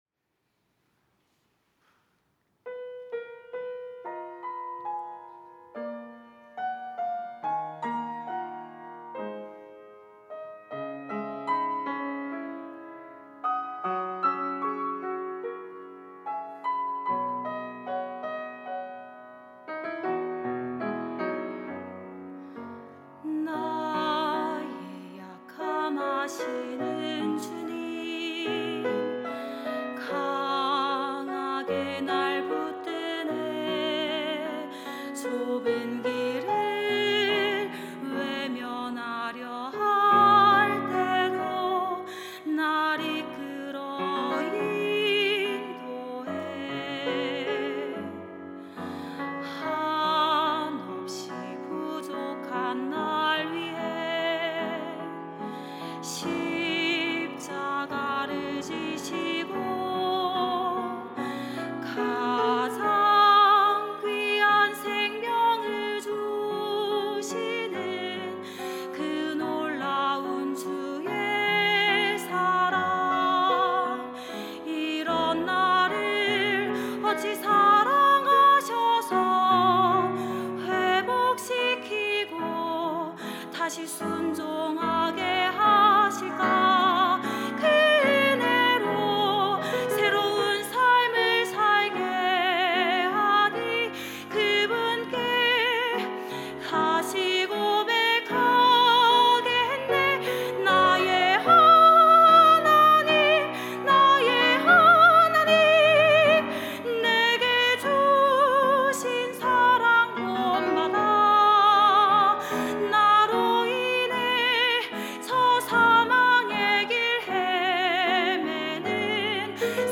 특송과 특주 - 믿음의 고백